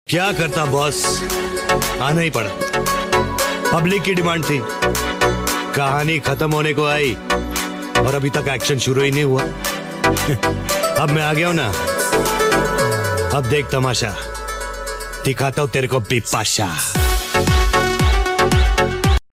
CG150 New Honda Bike 2026 Sound Effects Free Download